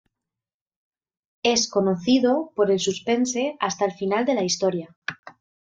Pronounced as (IPA) /susˈpense/